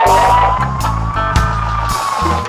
DUBLOOP 01-R.wav